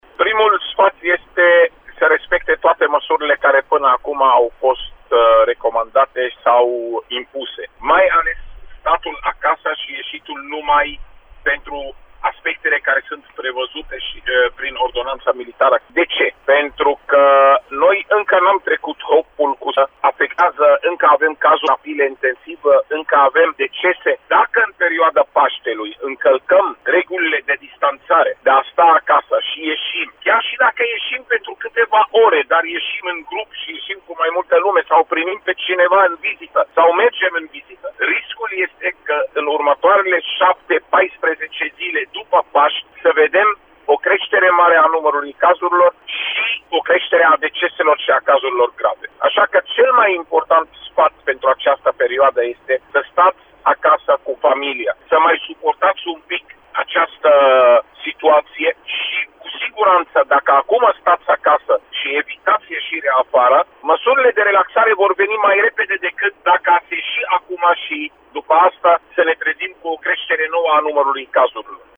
Dar acest lucru depinde doar de comportamentul populaţiei, explicã pentru Radio Reşiţa, Raed Arafat.